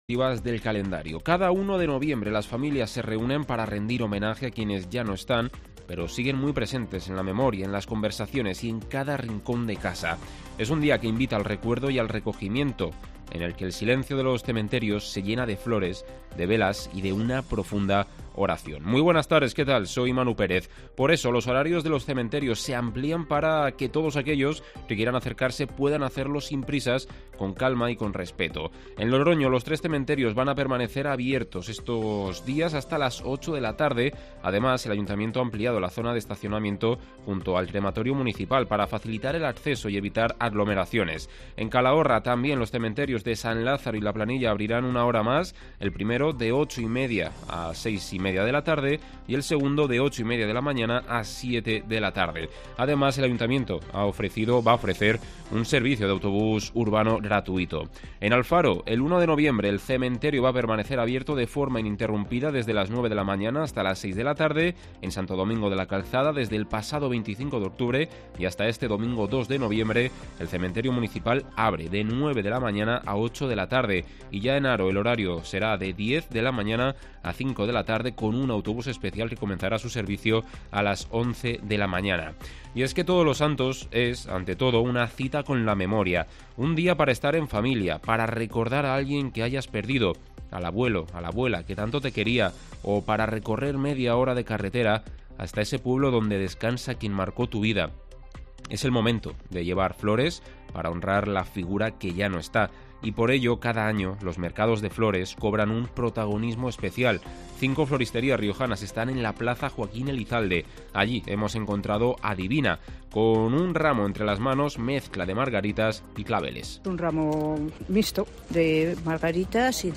COPE Rioja en el Mercado de las Flores